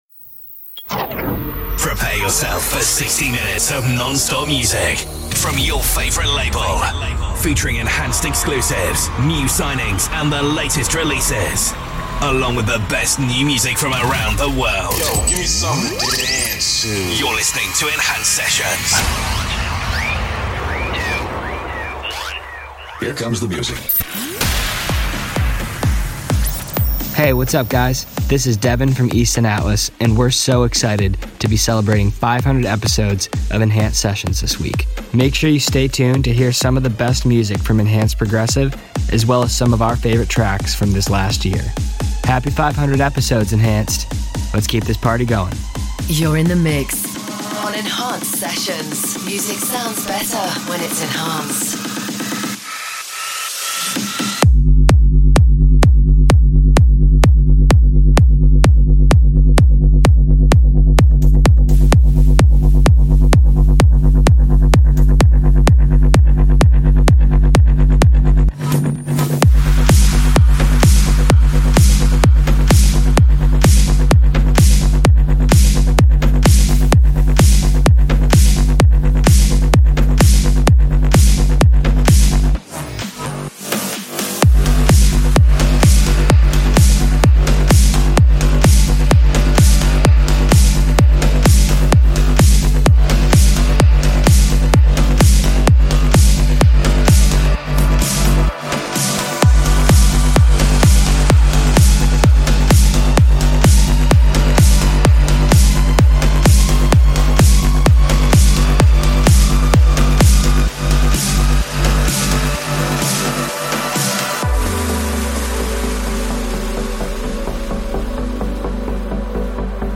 Also find other EDM Livesets, DJ Mixes and Radio